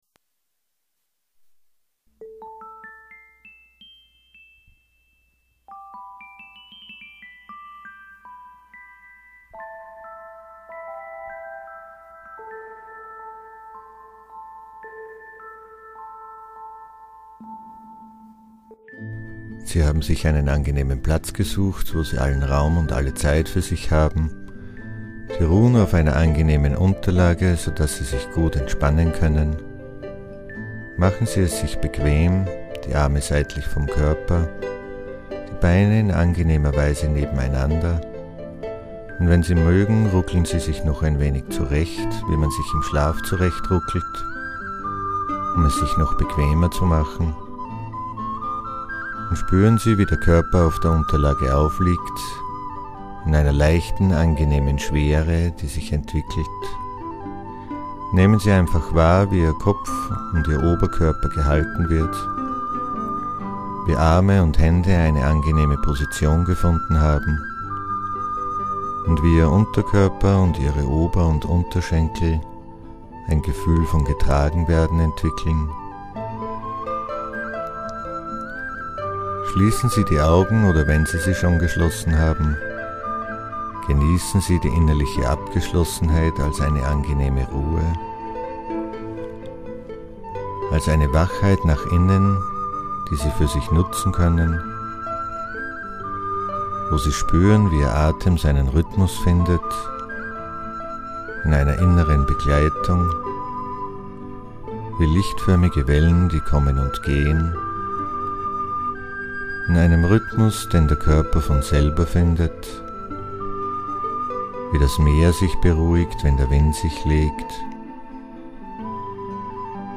Entspannungstrance